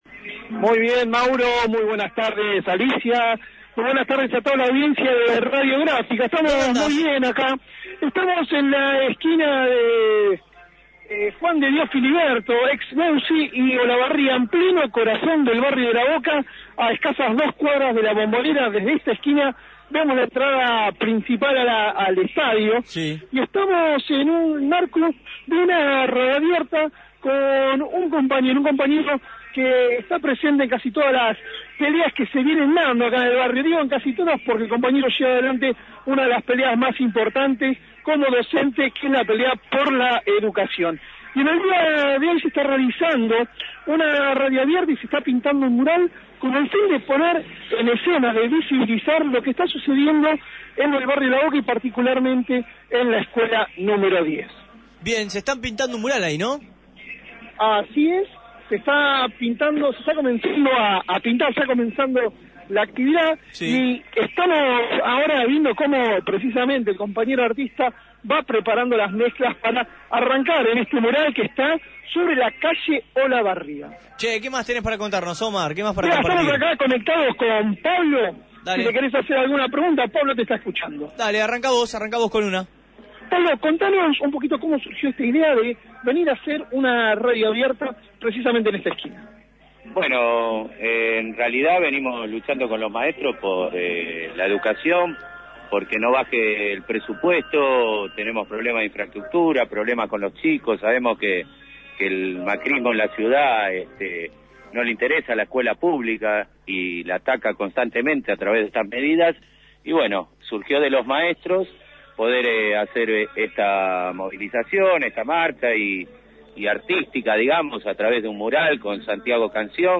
El pasado jueves 2 de julio docentes y vecinos de La Boca realizaron una jornada de protesta y visibilización de las condiciones en la que se encuentra las escuelas del barrio, a través de una radio abierta y la realización de un mural en la esquina de Olavarria y Juan de Dios Filiberto.